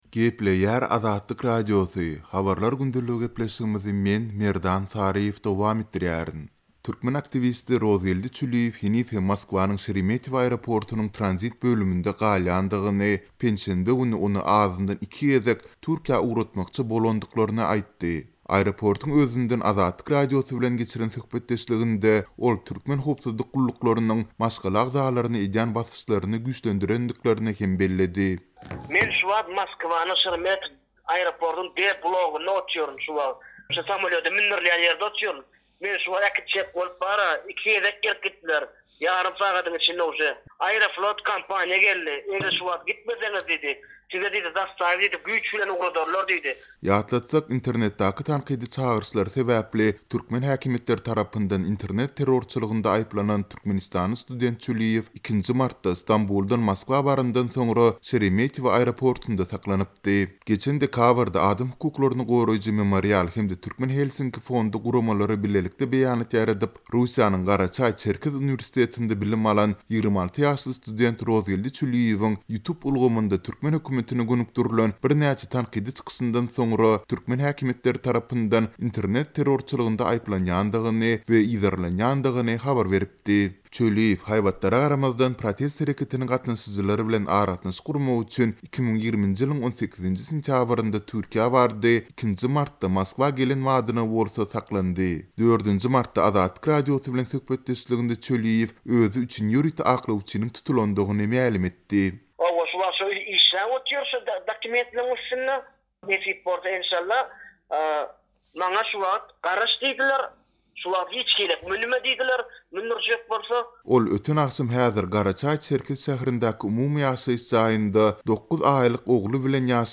Aeroportuň özünden Azatlyk Radiosy bilen geçiren söhbetdeşliginde, ol türkmen howpsuzlyk gulluklarynyň maşgala agzalaryna edýän basyşlaryny güýçlendirendiklerini hem belledi.